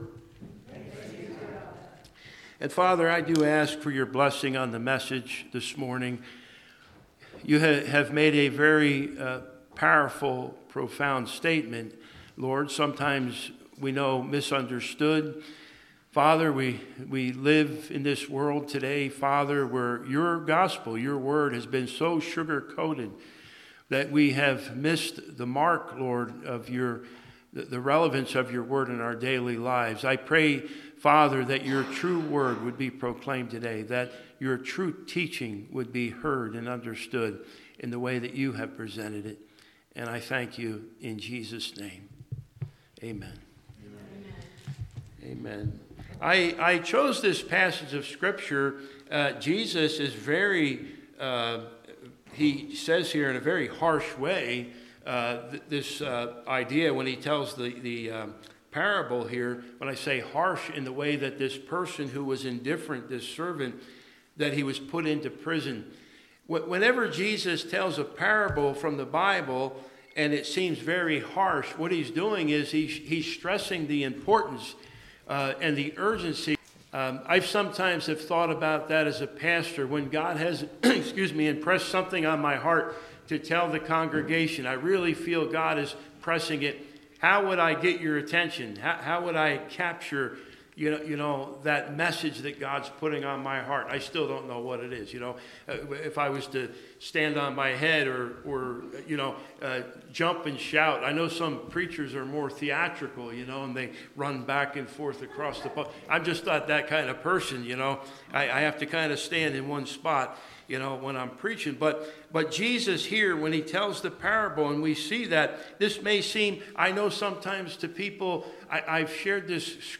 Worship Service – May 18, 2025 « Franklin Hill Presbyterian Church